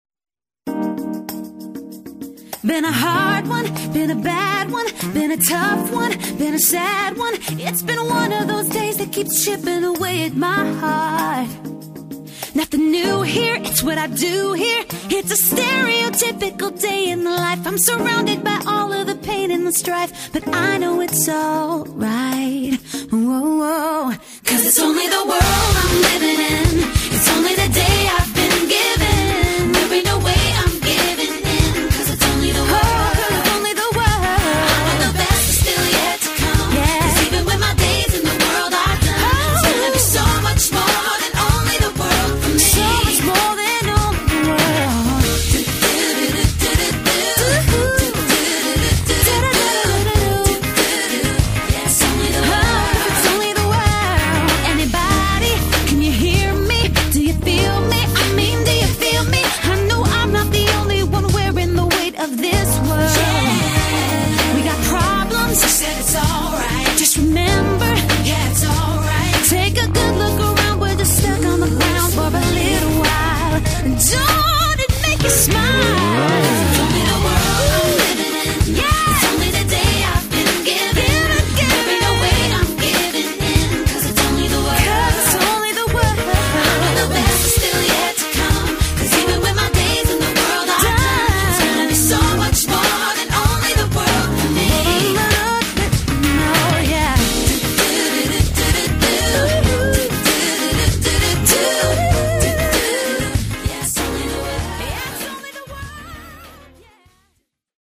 Demo Vocal